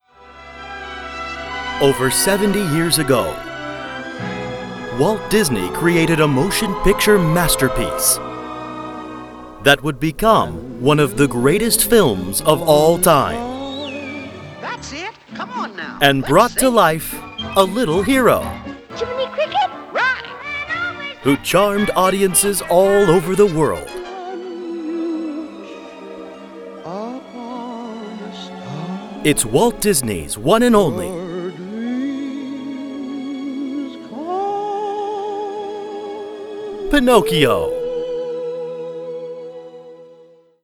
Male
Movie Trailers
Disney Trailer Style
Words that describe my voice are conversational, trustworthy, authoritative.